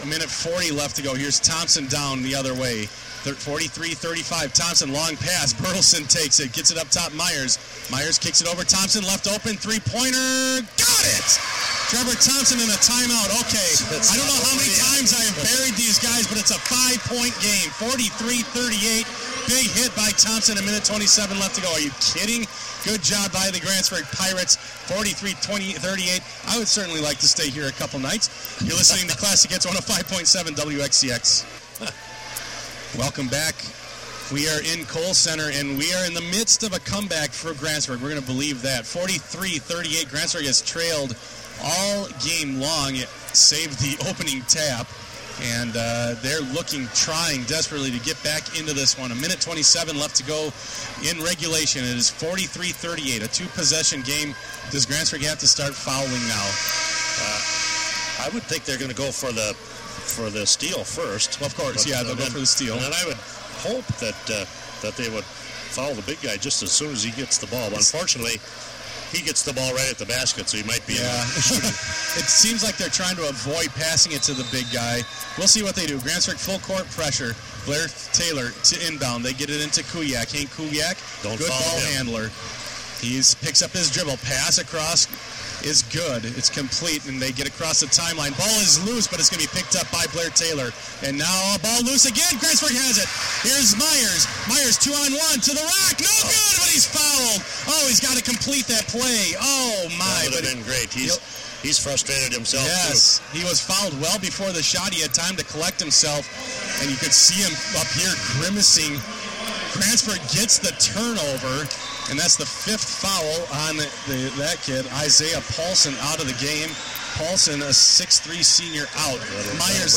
Replay: Grantsburg over Blair-Taylor State Semifinal 2011 radio broadcast — The WE ARE Network